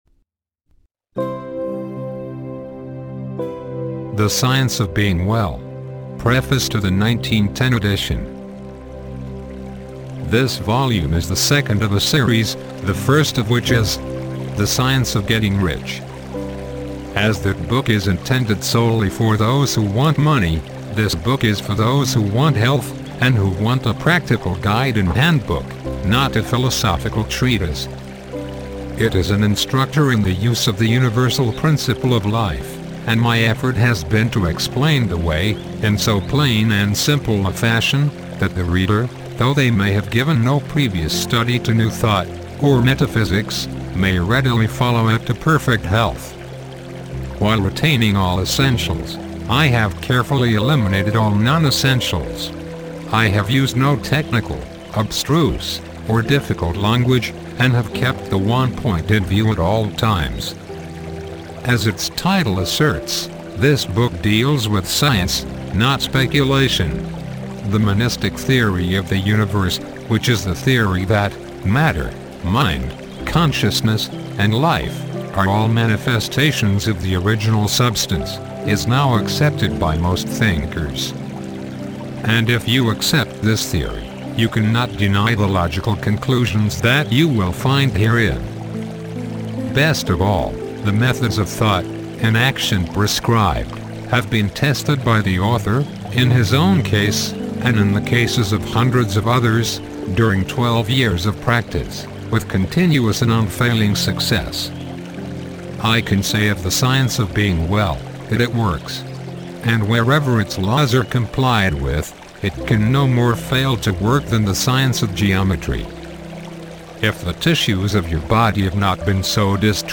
"Contents", "Preface to 1910 Edition", and "Preface to 2015 Edition", do not contain a subliminal voice
A synthesized voice is used, for maximum effect, without the possibility of unwanted tonal or expressive imprinting.